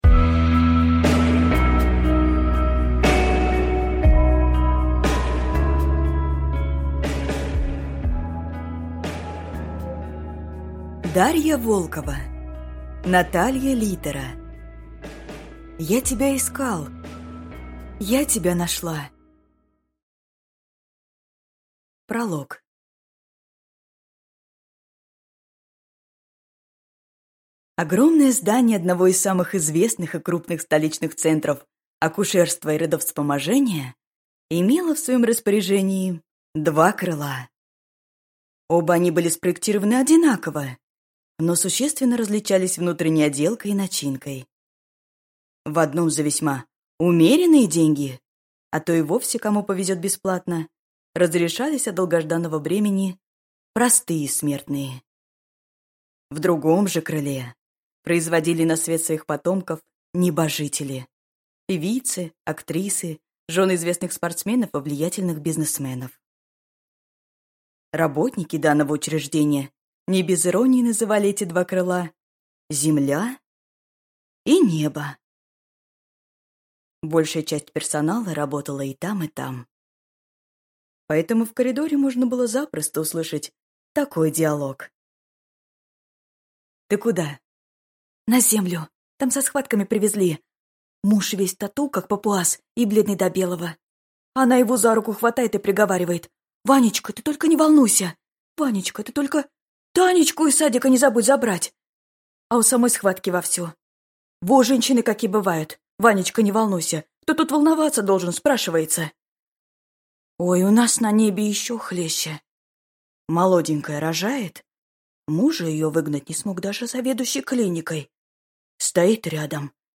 Аудиокнига Я тебя искал. Я тебя нашла | Библиотека аудиокниг